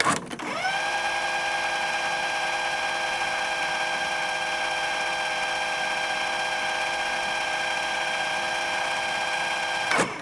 Casete avanzando rápido
cinta magnética
casete
Sonidos: Especiales